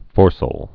(fôrsəl, -sāl)